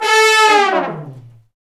Index of /90_sSampleCDs/Roland LCDP06 Brass Sections/BRS_Section FX/BRS_Fat Falls